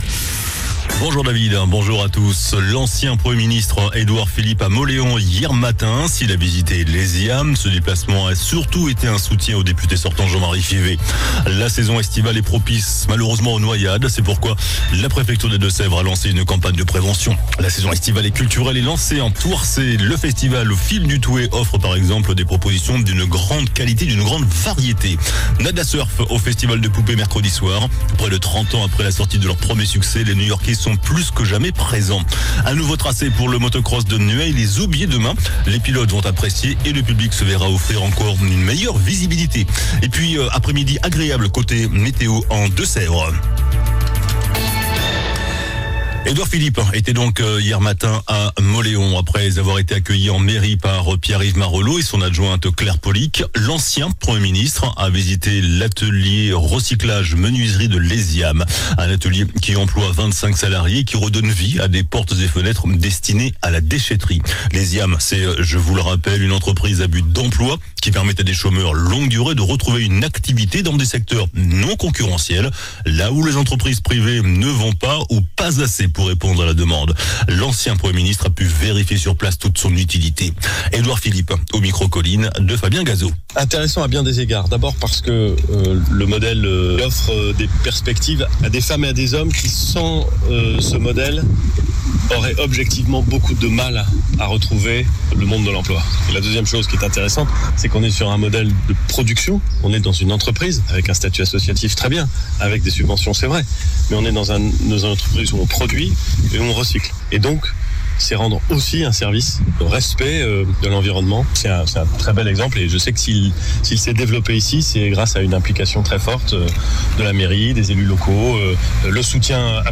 JOURNAL DU VENDREDI 05 JUILLET ( MIDI )